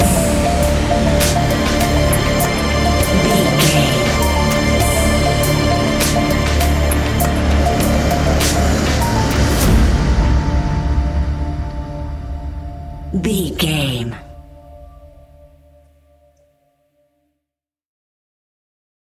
Fast paced
In-crescendo
Ionian/Major
industrial
dark ambient
EBM
drone
synths